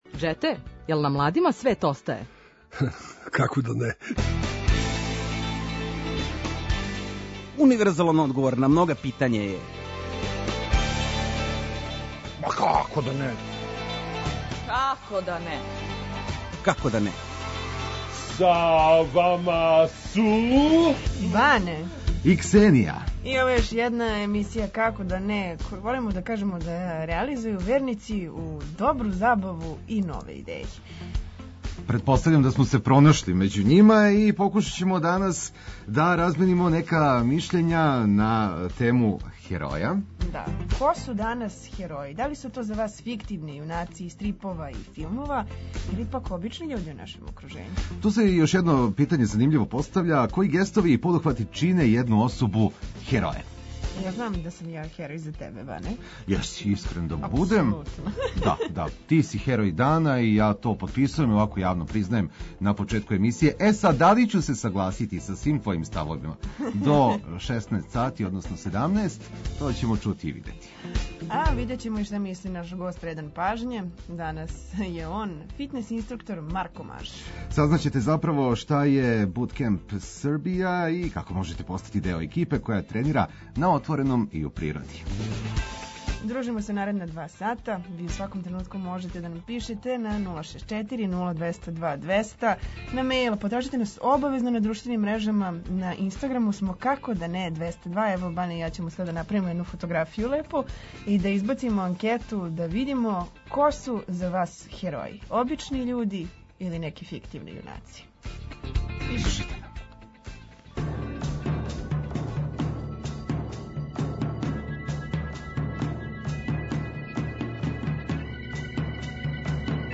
Окосница наше емисије је „Дебата” у којој ћемо разменити мишљење на различите теме и дилеме. Ко су за нас хероји?